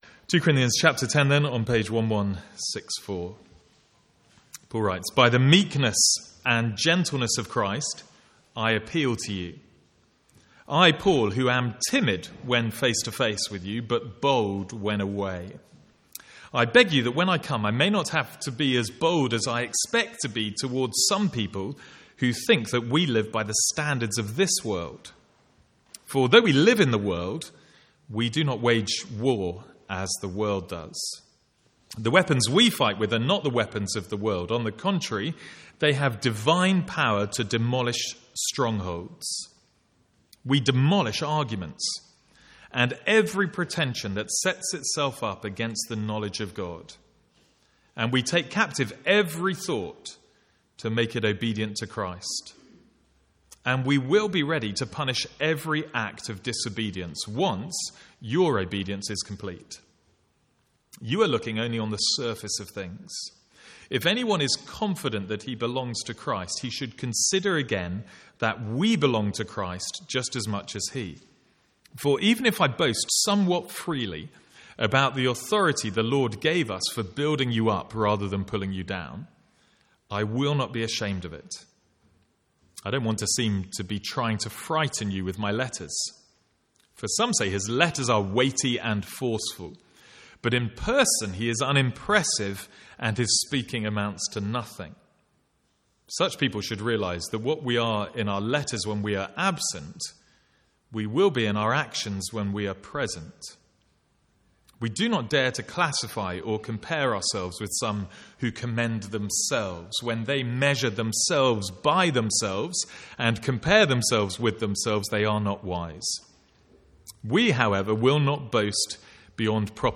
From the Sunday morning series in 2 Corinthians.